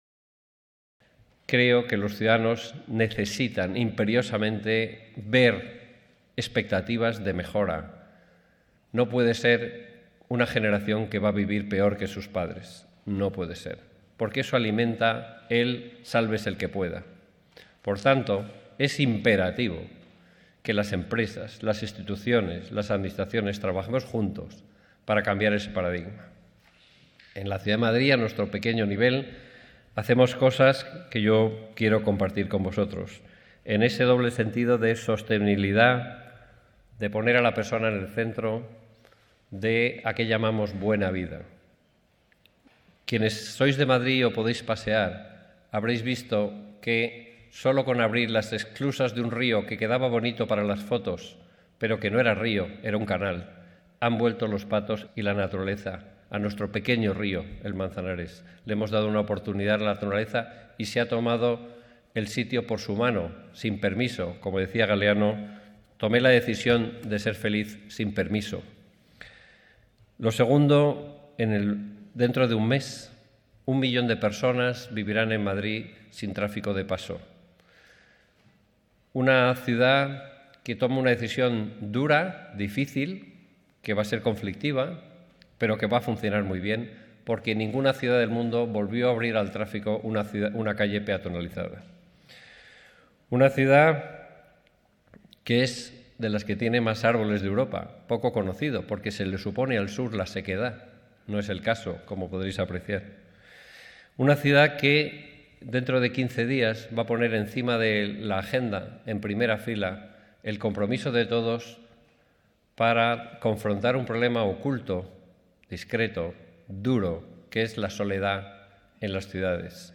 Con estas palabras el coordinador general de alcaldía, Luis Cueto, ha abierto el congreso internacional Sustainable Brands Madrid 2018, que durante dos días en CentroCentro disertará sobre sostenibilidad y negocio.